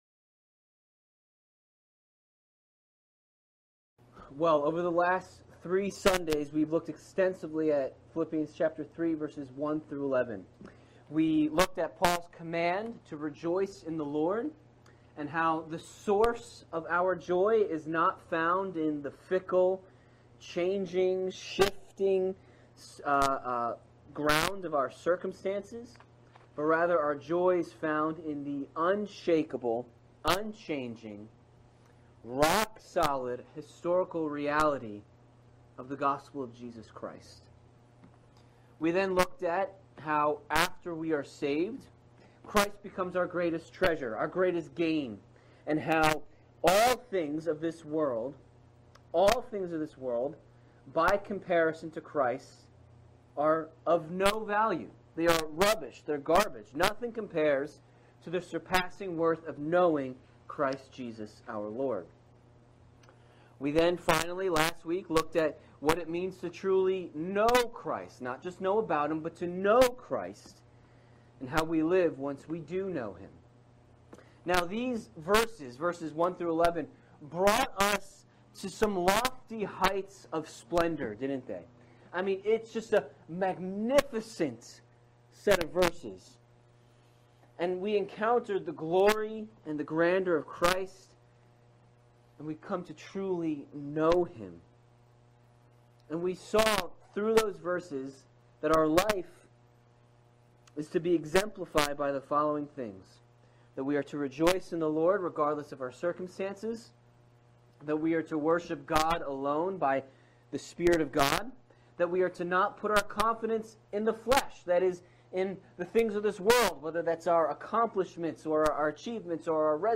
Sermons | Faith Bible Church